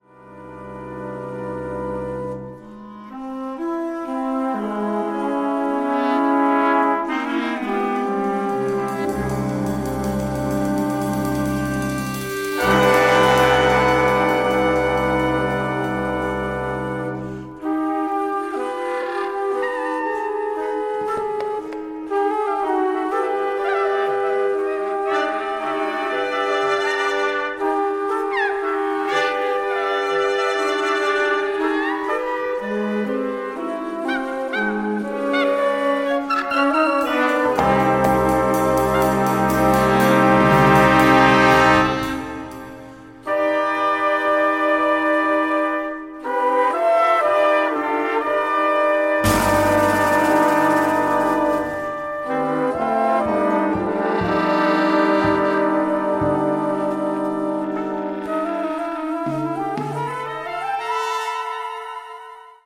saxophonist